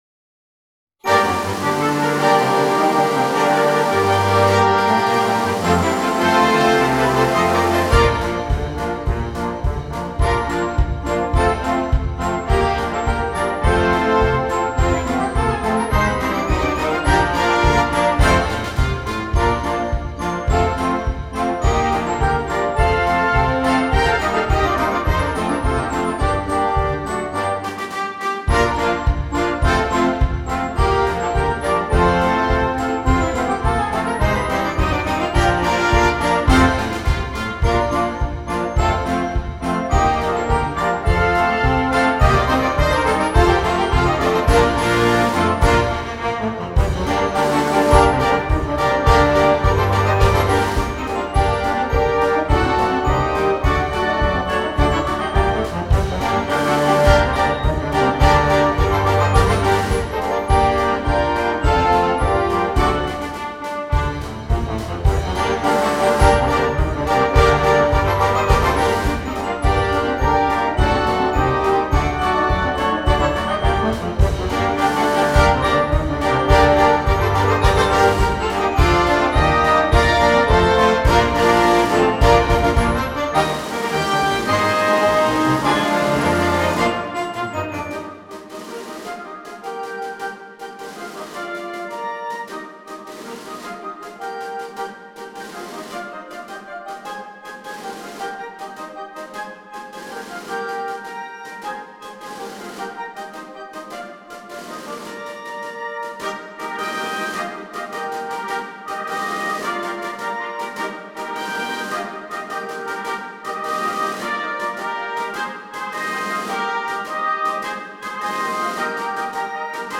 für großes Blasorchester…
Blasmusik
Konzertmarsch